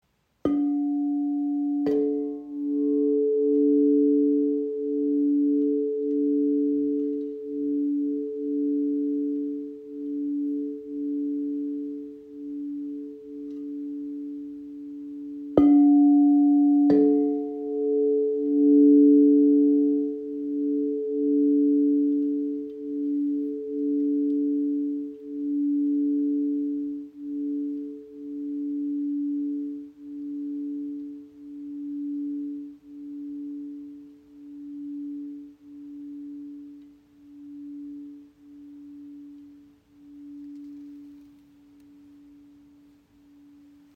Die Wave D/A in 432 Hz ist ein handgefertigtes Klanginstrument in der heiligen Quinte das Harmonie, Ruhe und Energiefluss schenkt.
Der Klang breitet sich weich und klar im Raum aus, ohne zu dominieren, und lädt Dich ein, langsamer zu werden, tiefer zu atmen und wieder bei Dir selbst anzukommen.